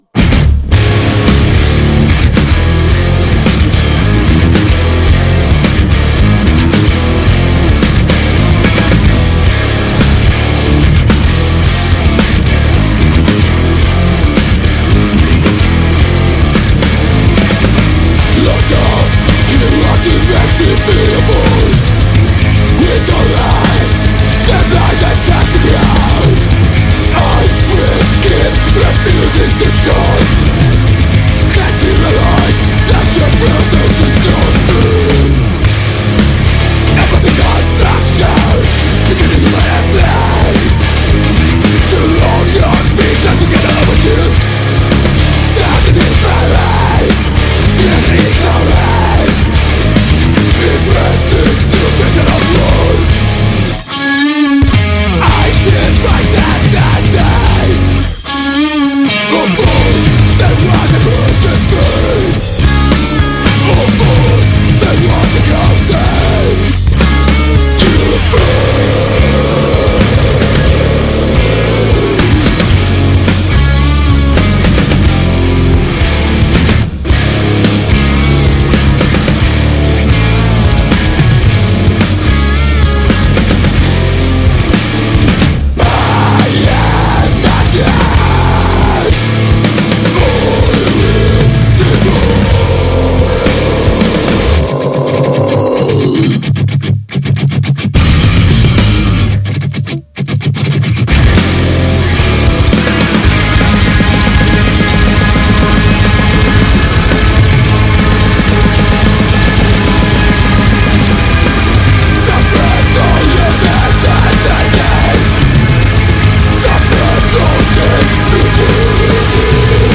Intervista ai Fate Unburied | 5-6-23 | Radio Città Aperta
intervista-fate-unburied-5-6-23.mp3